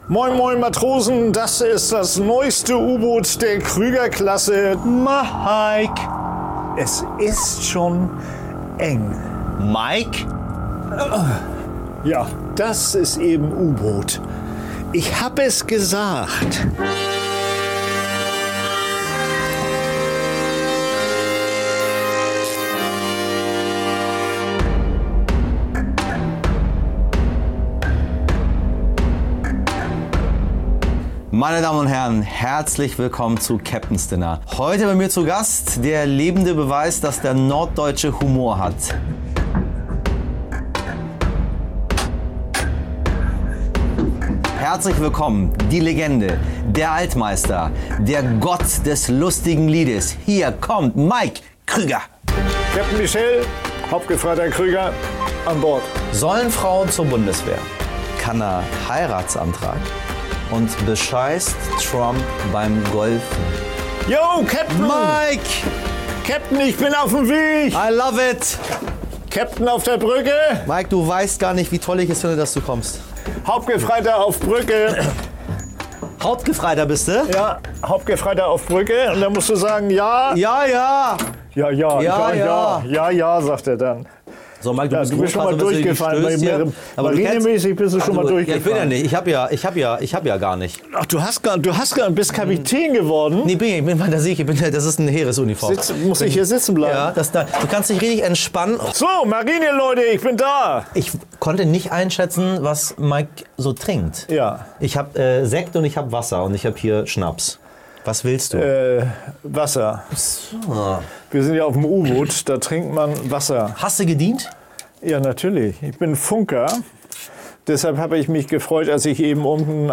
Bei Michel Abdollahi im U-Boot ist Komiker, Sänger und Schauspieler Mike Krüger zu Gast.